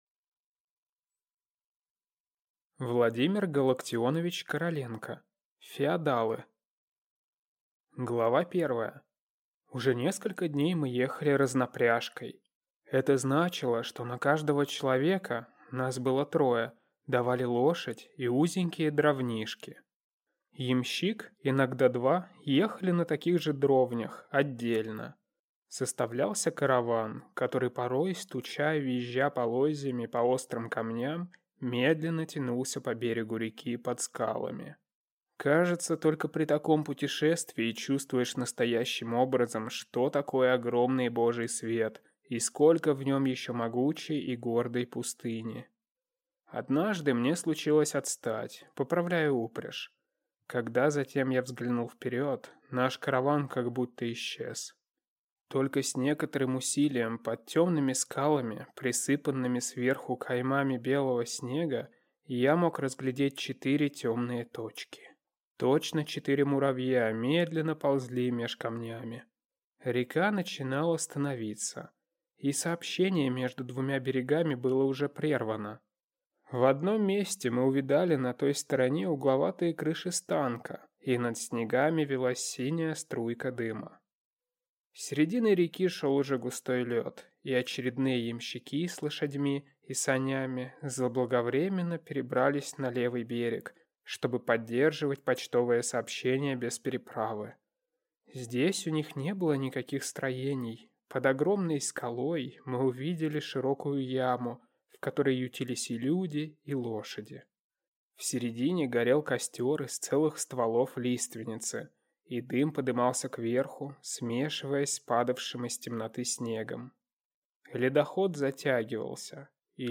Аудиокнига Феодалы | Библиотека аудиокниг